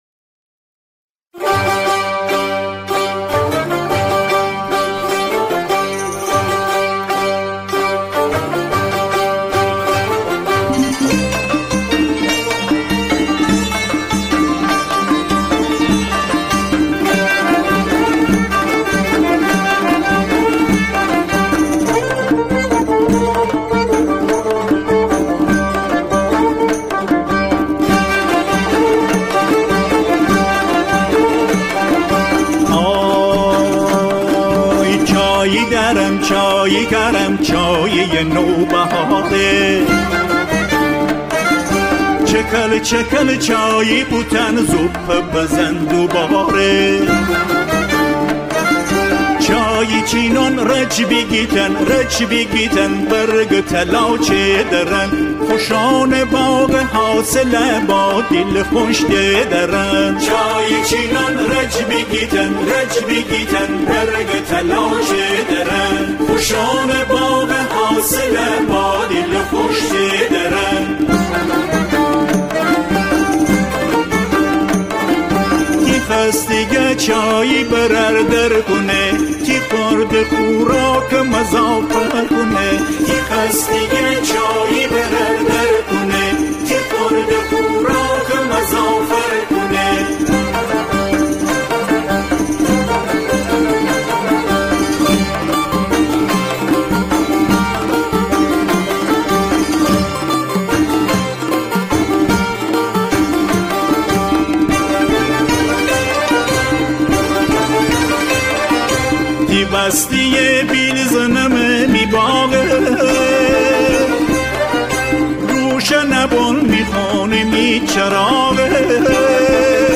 آنها در این قطعه، شعری را به گویش گیلکی اجرا می‌کنند.
اجرای گروه همخوان در این قطعه، برجسته و قابل توجه است.